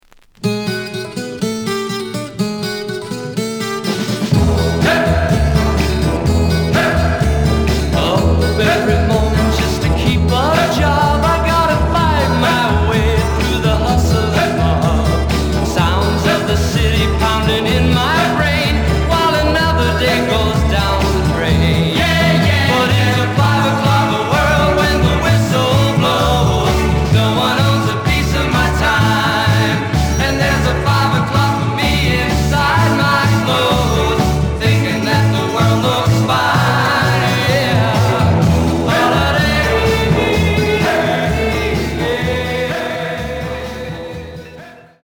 試聴は実際のレコードから録音しています。
●Format: 7 inch
●Genre: Rock / Pop